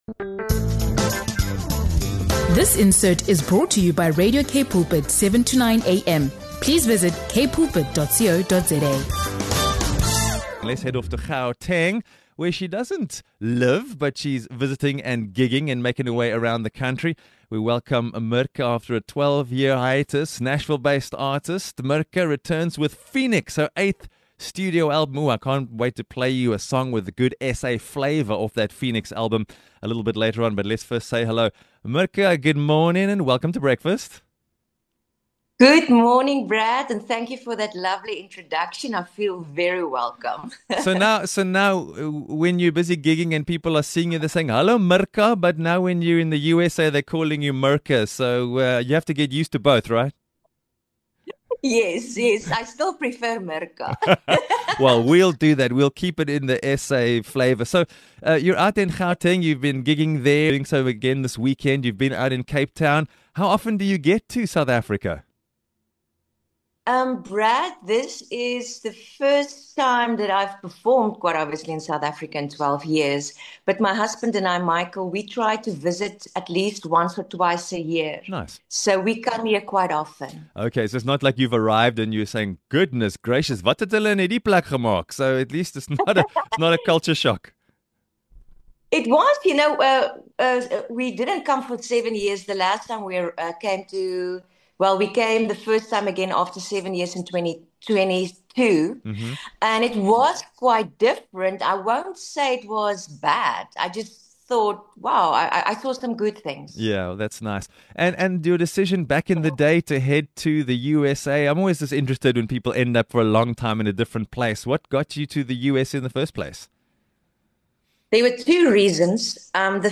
In this interview, she discusses the personal journey that led her away from and back to music, explaining the album's theme of resurrection and hope.